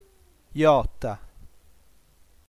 decima lettera dell’alfabeto sardo; esprime un suono semiconsonantico, l’approssimante palatale sonora, in caratteri IPA [j]); è presente solo in corpo di parola in mezzo a vocali in derivazione di alcune parole latine con -I- o -DI- intervocaliche (maju, arraju) o parole entrate in sardo da lingue moderne (casteddaju, pisciaju).